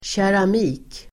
Ladda ner uttalet
Uttal: [tjeram'i:k]